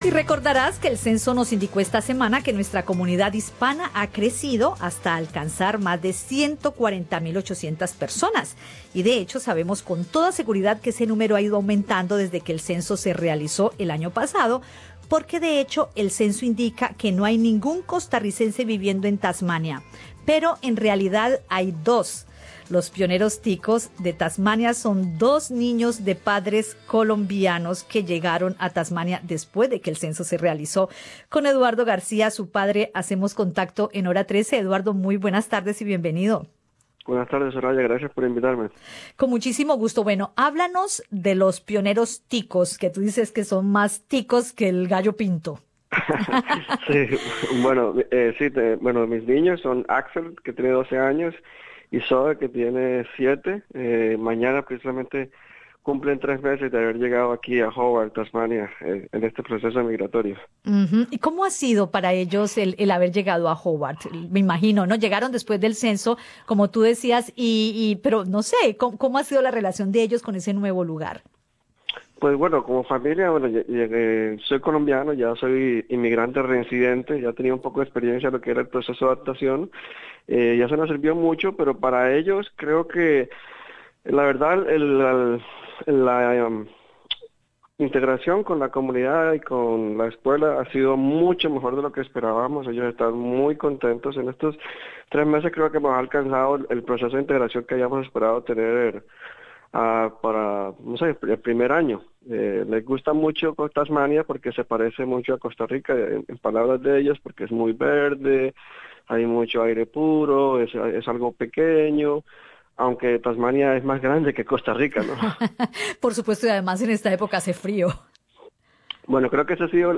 Escucha el podcast con la entrevista